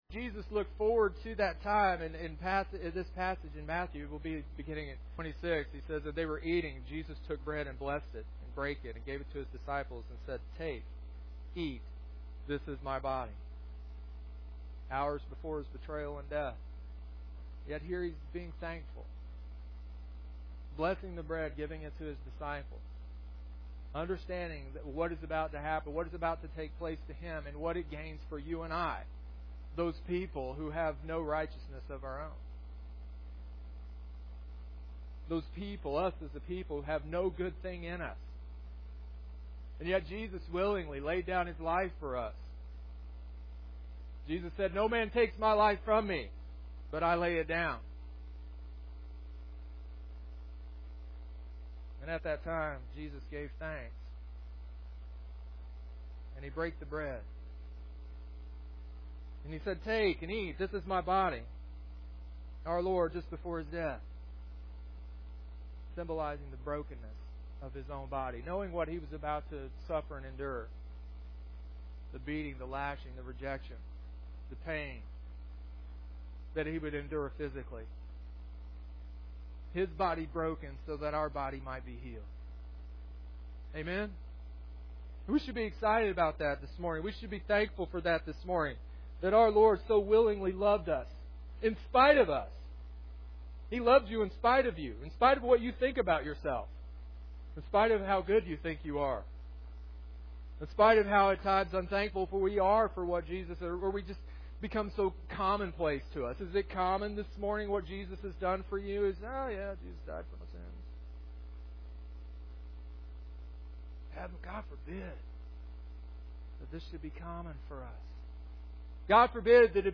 Communion Service (edited, about 6 minutes)